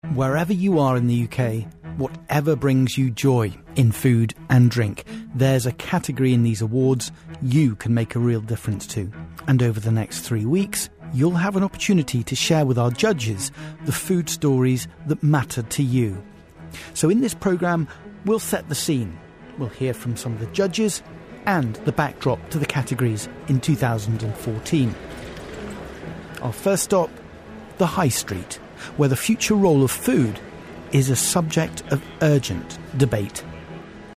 【英音模仿秀】美食拯救街道 听力文件下载—在线英语听力室